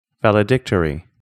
valedictory /20100/ /ˌvæl.ɪ.’dɪk.tər.i/ /ˌvæl.ɪ.’dɪk.tər.i/
valedictory-3.mp3